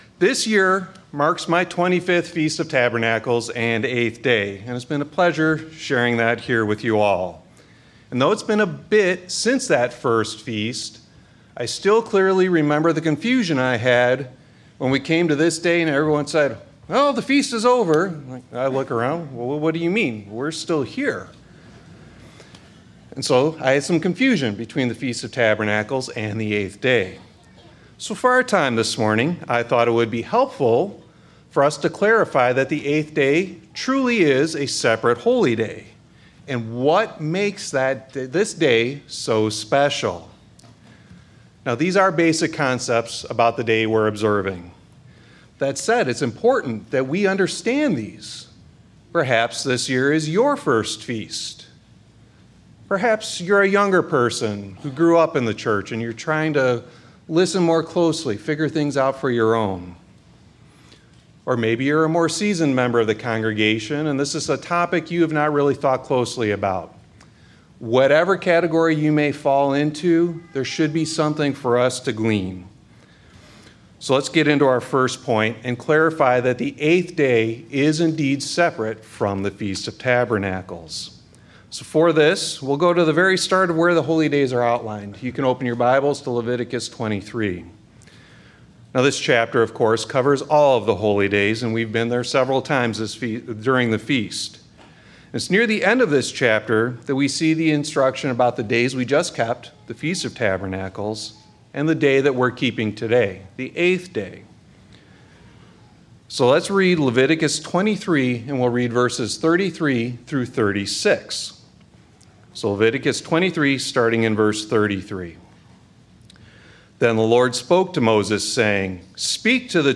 Sermons
Given in Branson, Missouri